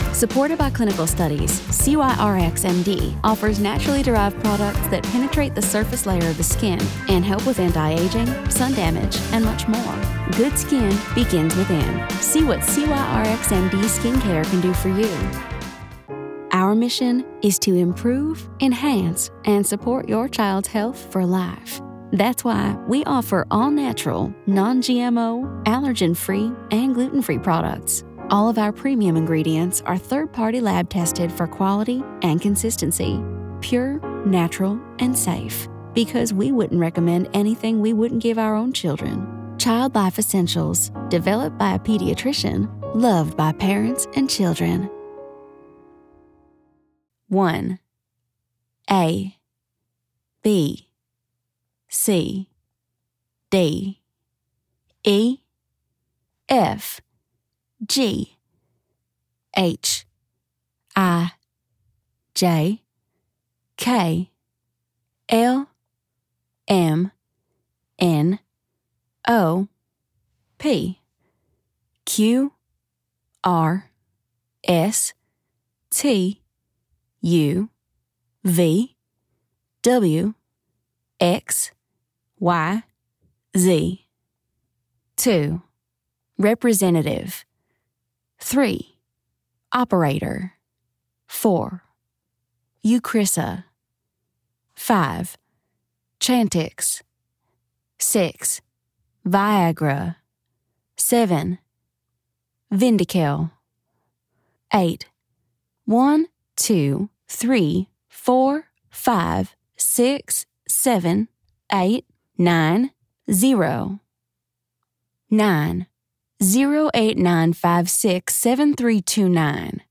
southern us